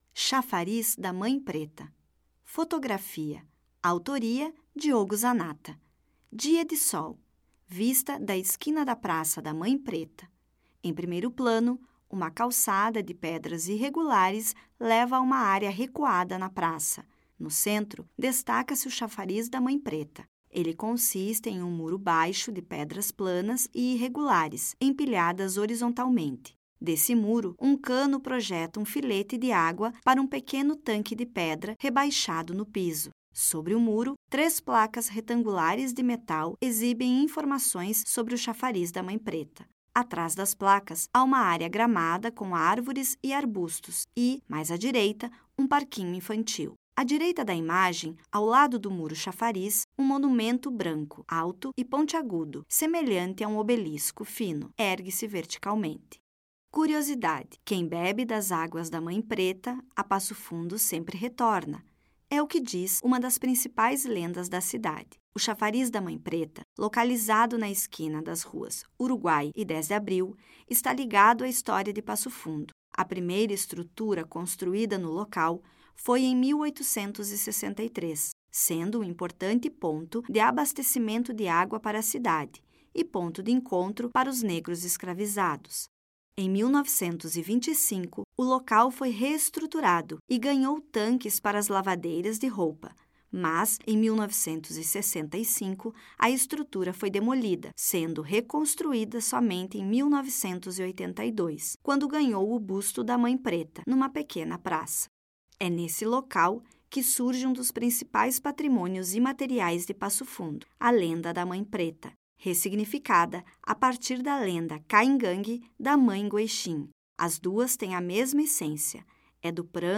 Audioguia
Audiodescrição